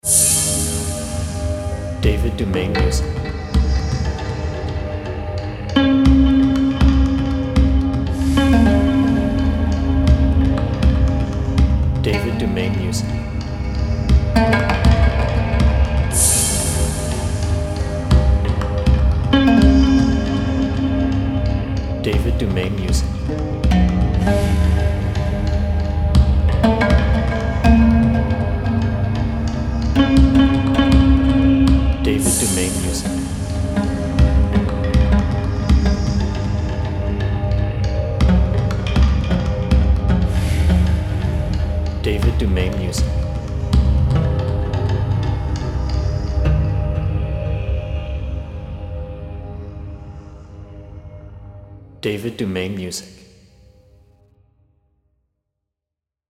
1. Add a voice-over layer using your own voice